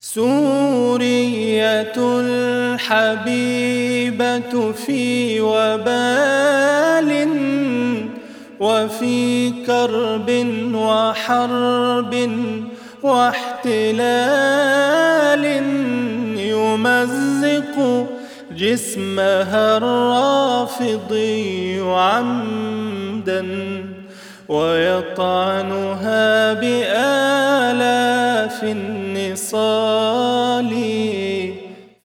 أناشيد